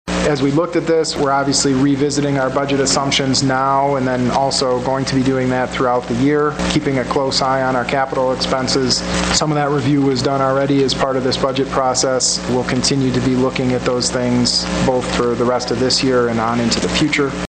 Inflation and a tight labor market are also issues that the City had to figure into this budget. Kuk says they will keep looking at the budget during the course of the year.